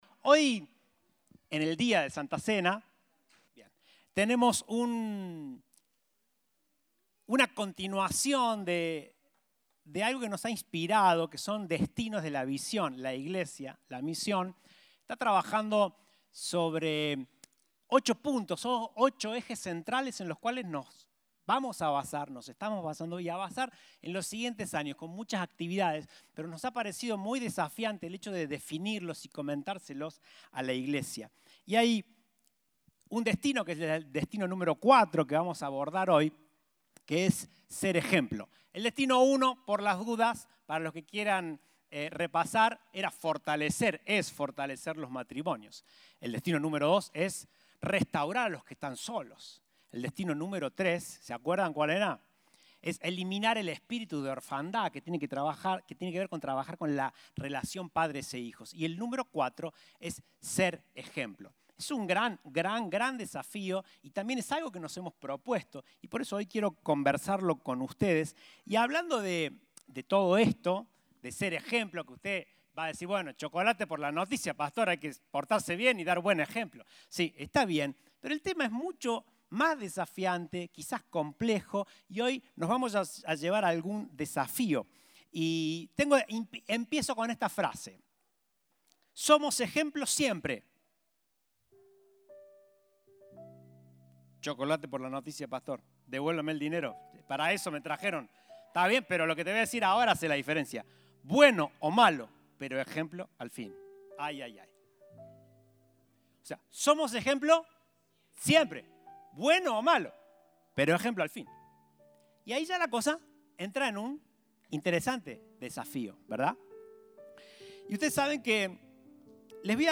Compartimos el mensaje del Domingo 1 de Mayo de 2022.